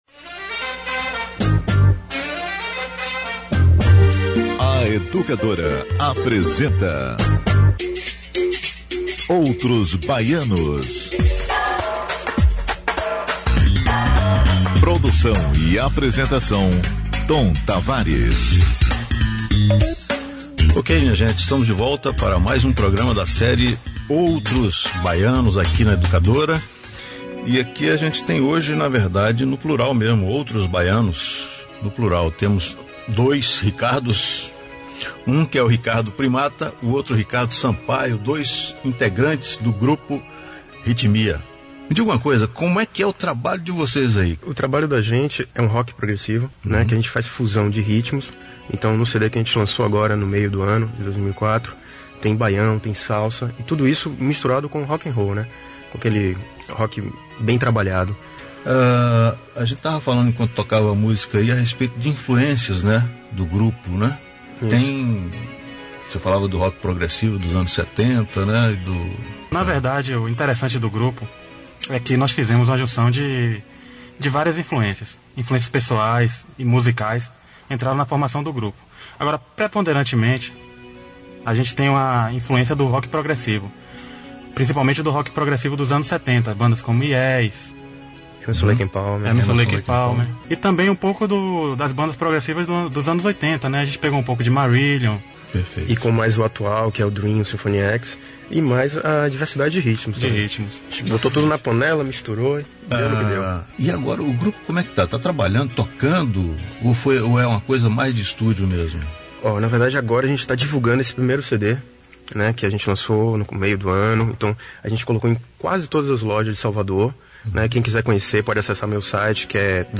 Entrevista-para-a-Radio-Educadora-FM-Programa-Outros-Baianos.mp3